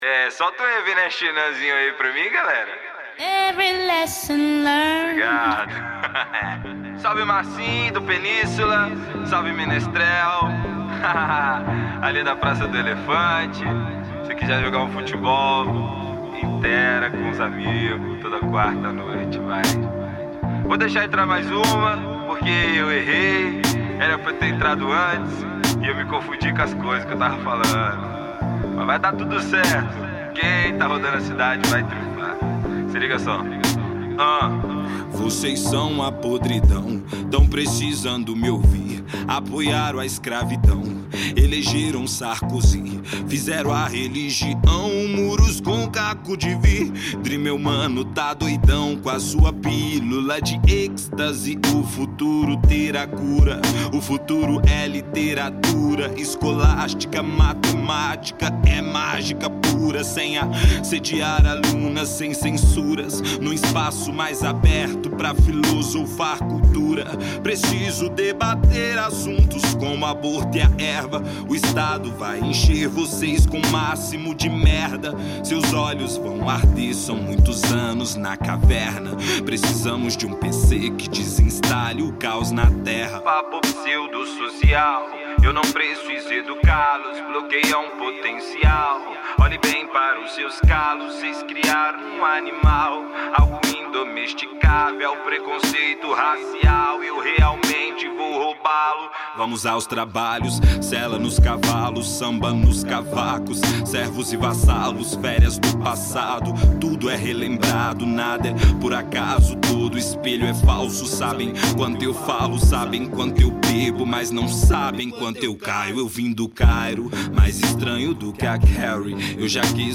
2025-03-22 15:55:39 Gênero: Rap Views